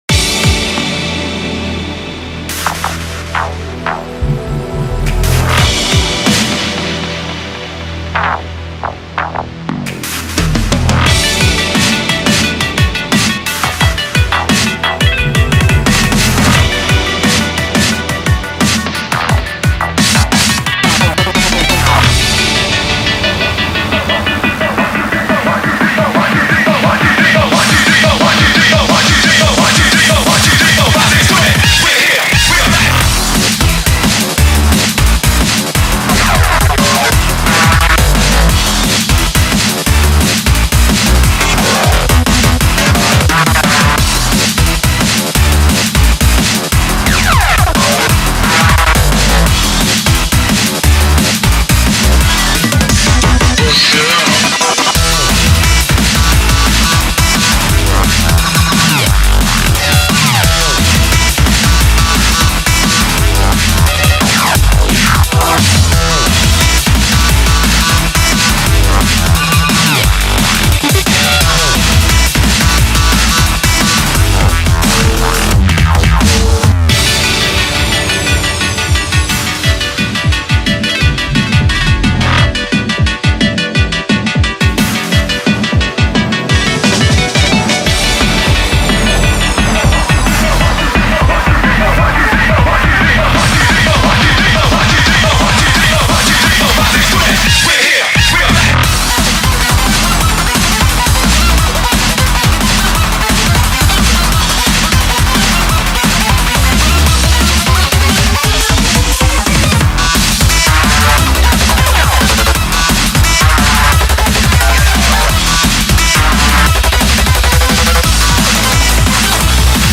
BPM88-175
Audio QualityPerfect (High Quality)
Comments[DRUM & BASS]